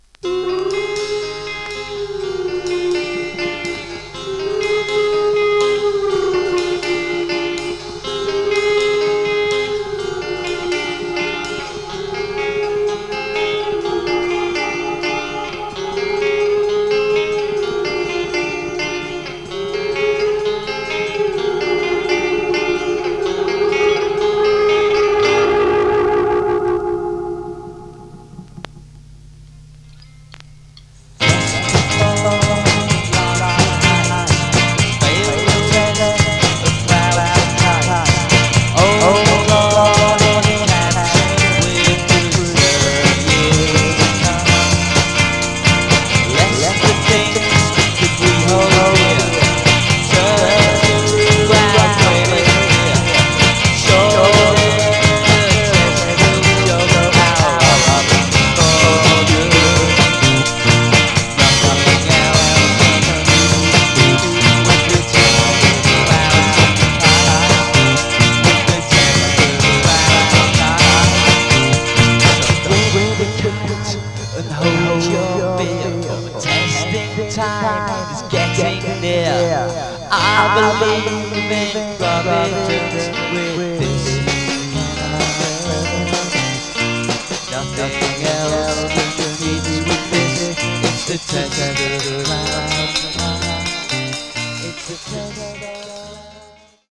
このEPは彼らの初期の代表作の一つで、幻想的でエコーかかったサウンドと実験的なアレンジが特徴。